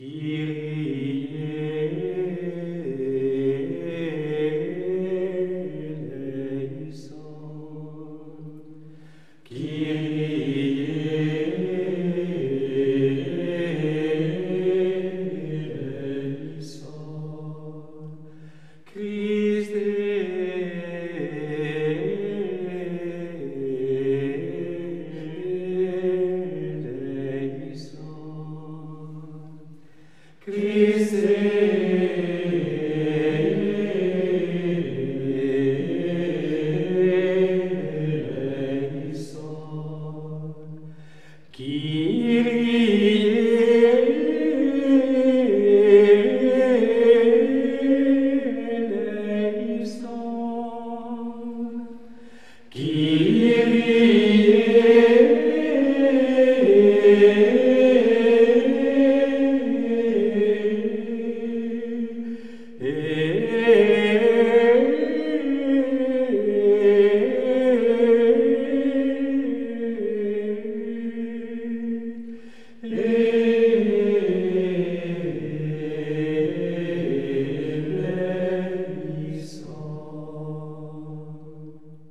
Voilà un beau Kyrie du 1er mode, tout en progression intensive, du début jusqu’à la fin.
Il faut partir piano sur ce premier Kyrie très doux, très humble, je dirais aussi volontiers très mystérieux.
Par contre, d’emblée une grande légèreté, une grande souplesse.
Les deux avant-derniers Kyrie font jaillir alors un magnifique élan, très lyrique, très mystique même, que rien ne laissait vraiment prévoir.
Le tout dernier Kyrie reprend la mélodie de l’avant-dernier, redouble cette mélodie, en crescendo, c’est là le sommet intensif de toute la pièce, puis va rejoindre le Ré grave pour la cadence finale, de façon vraiment magnifique, dans la grande paix du 1er mode.
Ce merveilleux Kyrie, après un élan de toute beauté, retrouve la profonde paix du début sur sa finale.